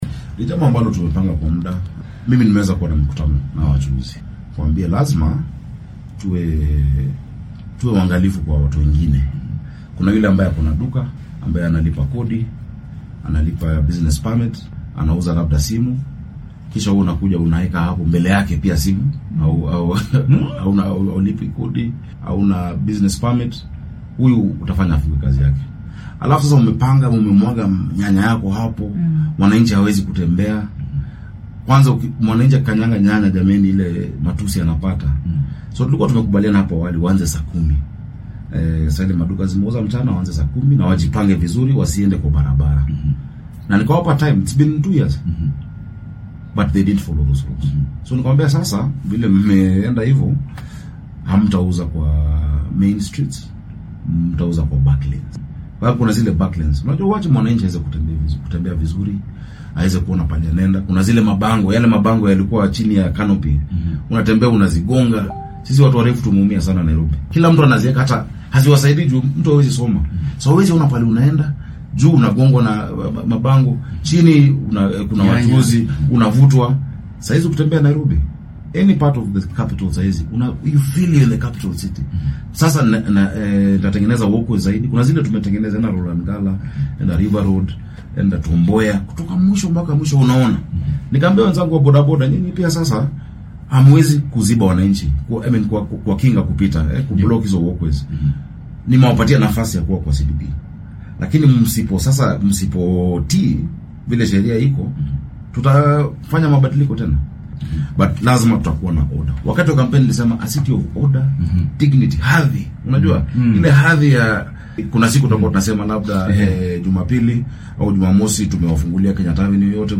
Barasaabka ismaamulka Nairobi Jonson Sakaja oo saaka wareysi gaar ah siinayay idaacadda maxalliga ee Radio Citizen ayaa sheegay in aan waratada gebi ahaanba laga eryin bartamah caasimadda dalka balse keliya la nidaamiyay ganacsigooda si loo xalliyo ciriiriga isku socodka gaadiidka iyo dadweynaha ee ka jiro magaala madaxda waddanka.